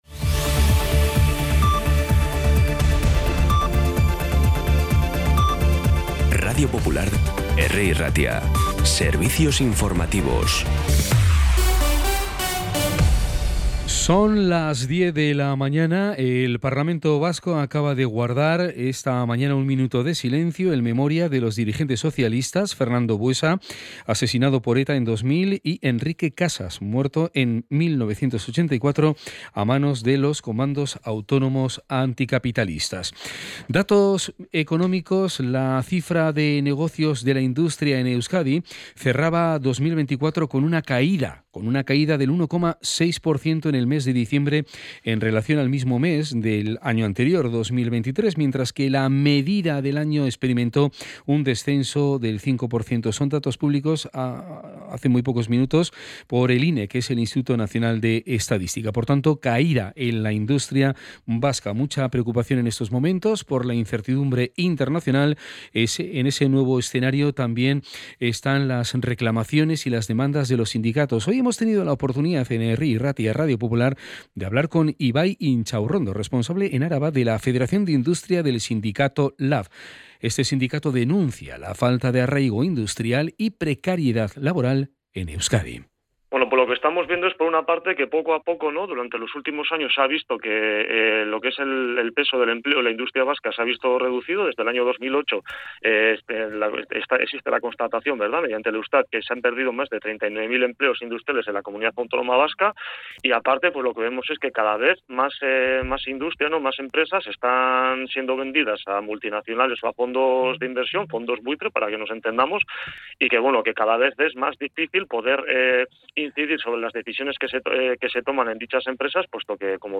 Las noticias de Bilbao y Bizkaia del 21 de febrero a las 10
Los titulares actualizados con las voces del día. Bilbao, Bizkaia, comarcas, política, sociedad, cultura, sucesos, información de servicio público.